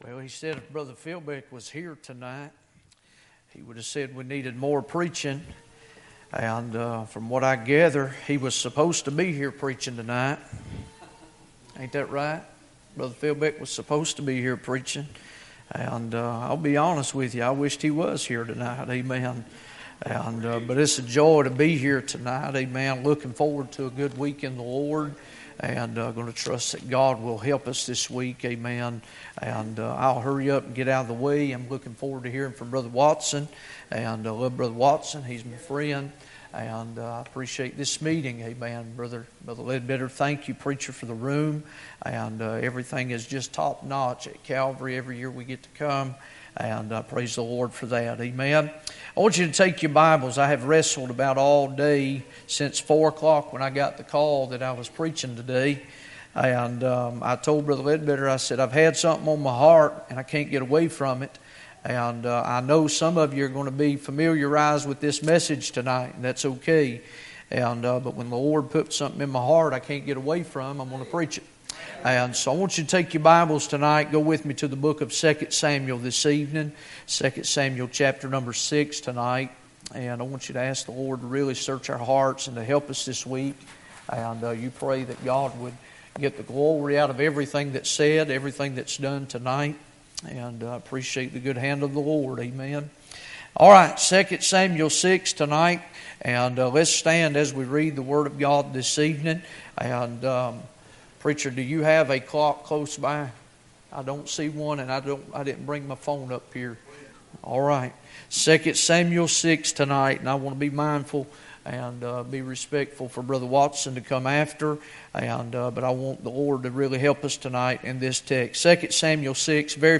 SERMON AND TEACHING AUDIO: